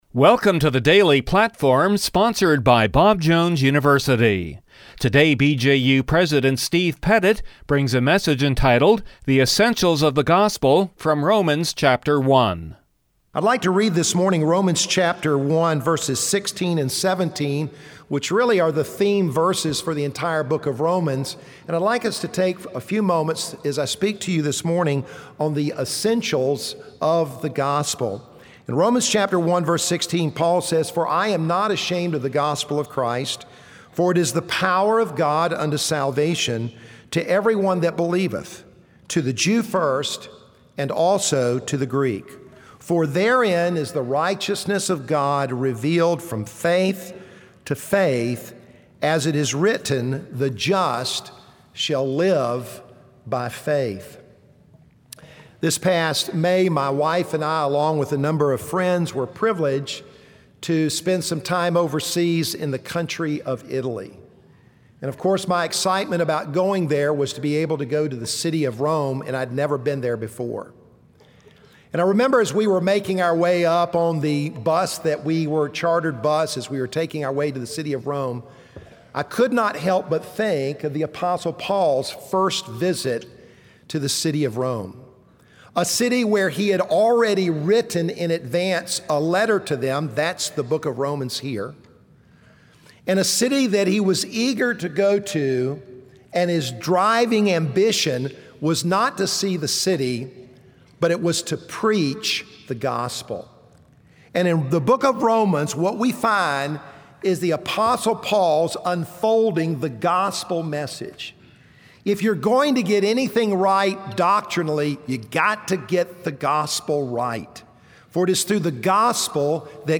From the chapel service on 01/10/2018